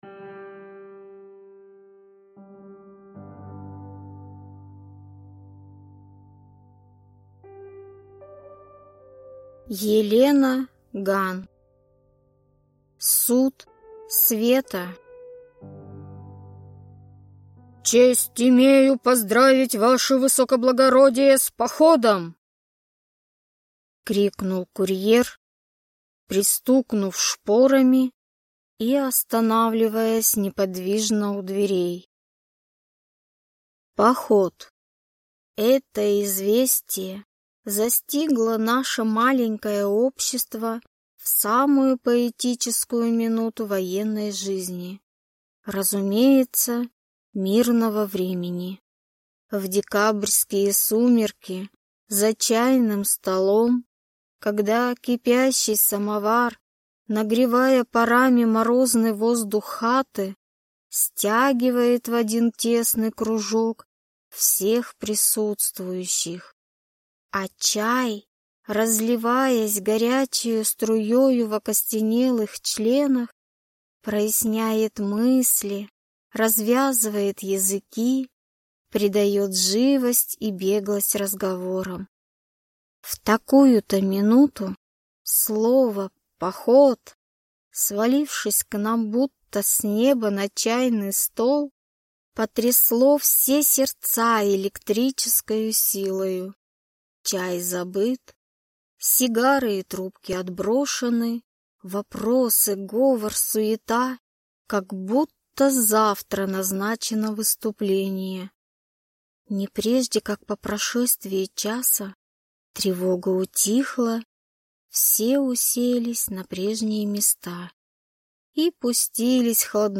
Аудиокнига Суд света | Библиотека аудиокниг